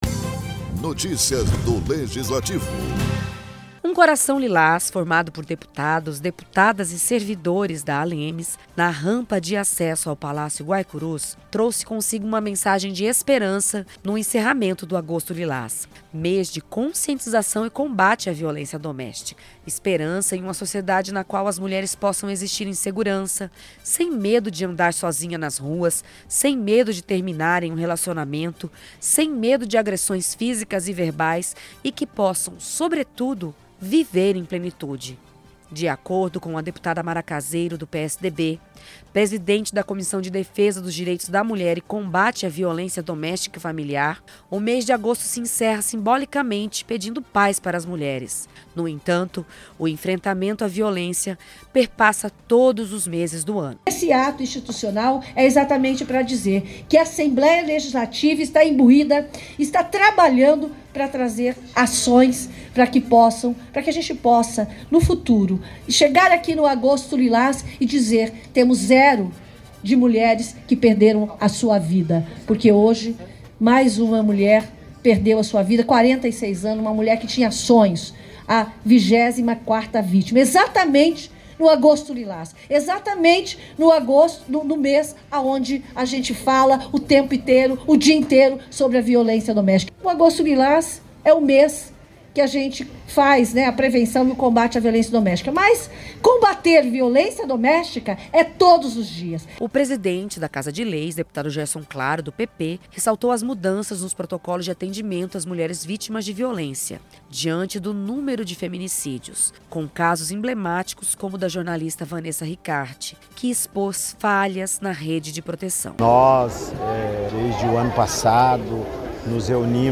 Download Produção e Locução